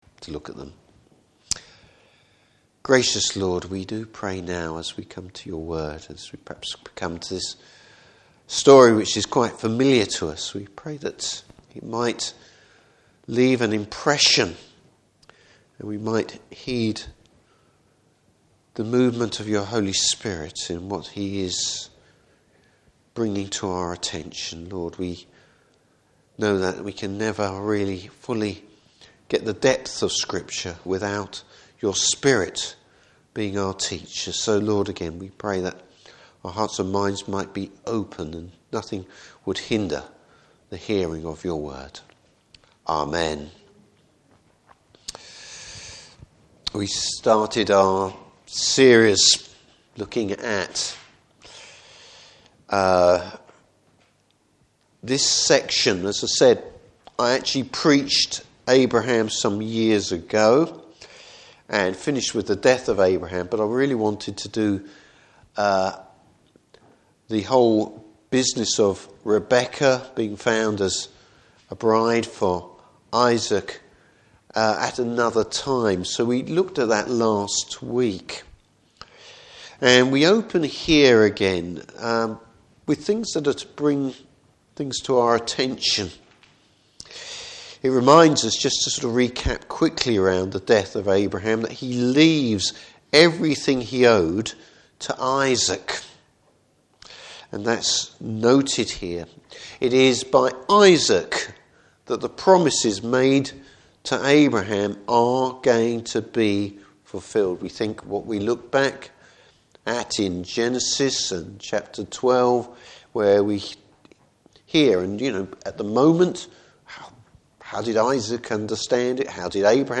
Service Type: Evening Service Esau’s disregard for his birth right.